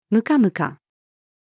ムカムカ-female.mp3